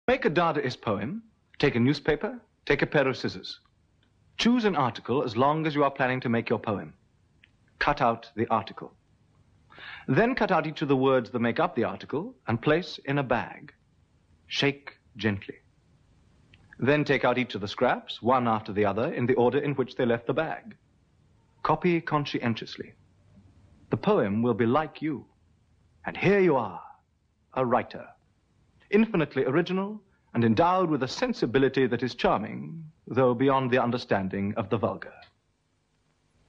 Tristan Tzara എഴുതിയ മാനിഫെസ്റ്റോയെ കുറിച്ച് അദ്ദേഹം തന്നെ പറയുന്ന ശബ്ദരേഖ കേള്‍ക്കുക രസകരമാണ്.